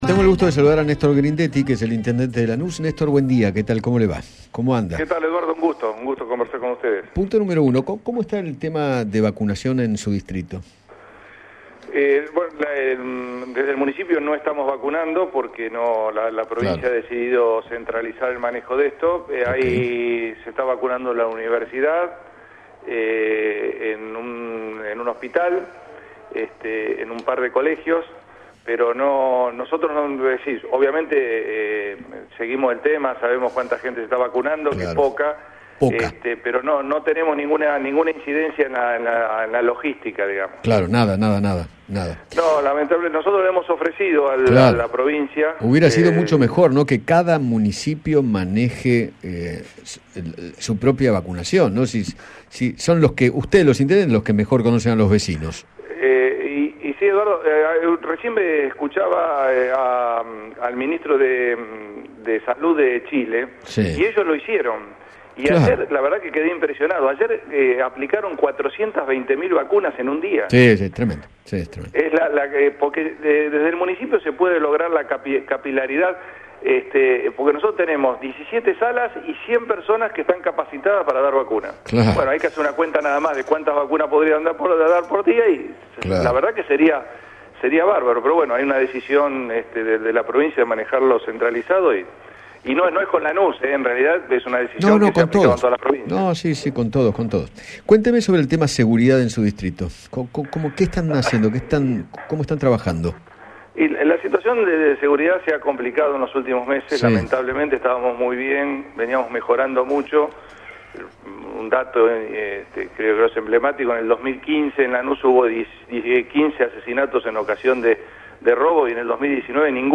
Néstor Gridetti, intendente de Lanús, dialogó con Eduardo Feinmann sobre el proceso de vacunación en Provincia e hizo hincapié en las complicaciones para colaborar desde su municipio. Además, se refirió al aumento de la inseguridad.